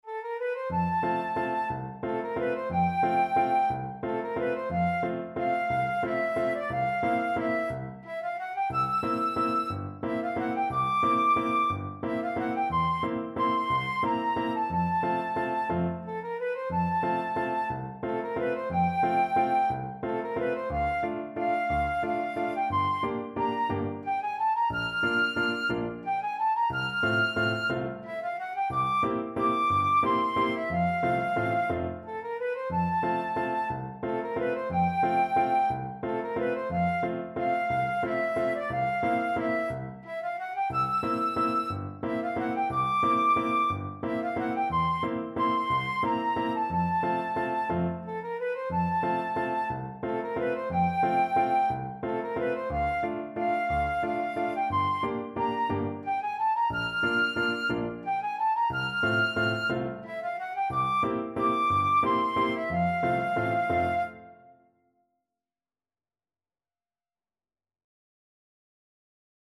Flute version
3/4 (View more 3/4 Music)
One in a bar .=c.60